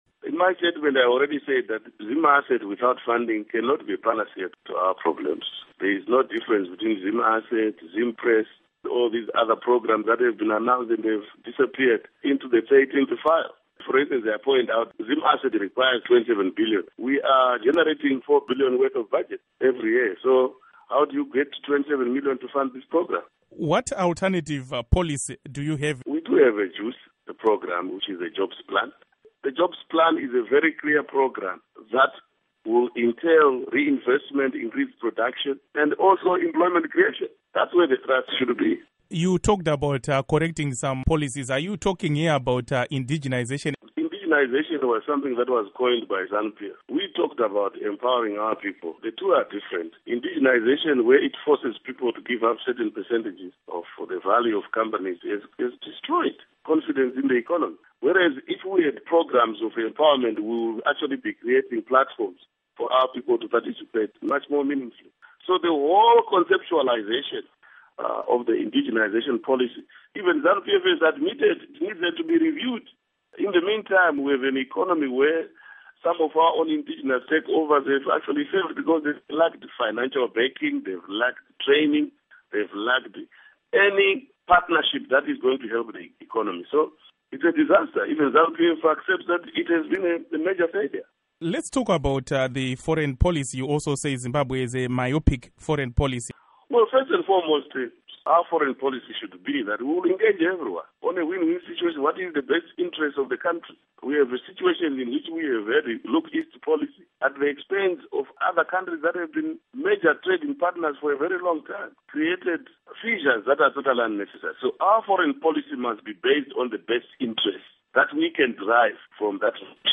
Interview With Morgan Tsvangirai